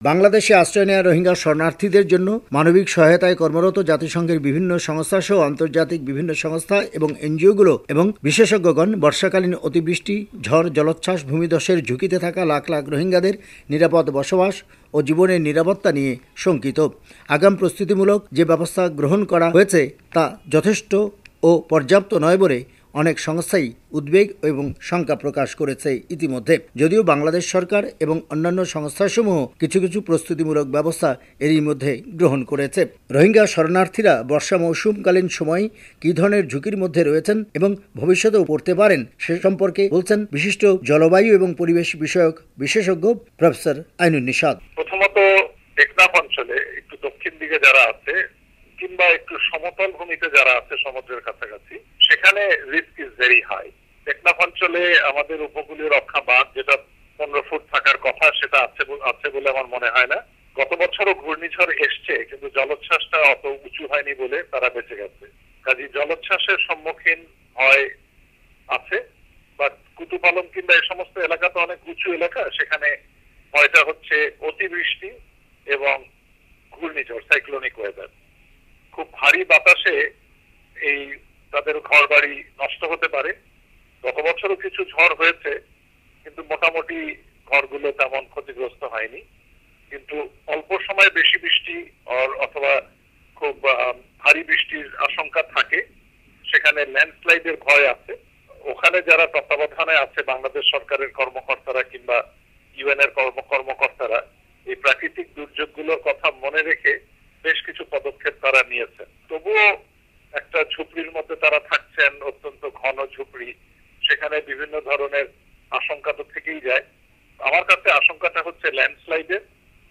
বাংলাদেশ সরকারের রোহিঙ্গা বিষয়ক শরণার্থী ত্রাণ ও প্রত্যাবাসন কমিশনার মোহাম্মদ আবুল কালাম মনে করেন, আগের বছরের তুলনায় এ বছরের বর্ষা মৌসুমে রোহিঙ্গা শরণার্থীদের জন্য সামগ্রিক প্রস্তুতি অনেক ভালো।